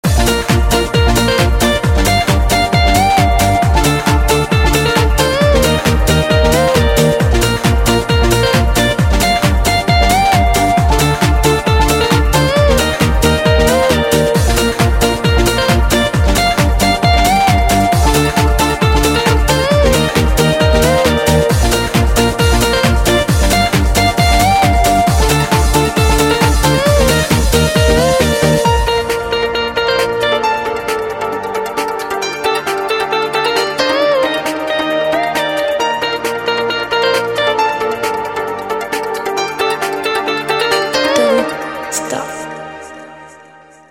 • Качество: 128, Stereo
красивые
dance
спокойные
electro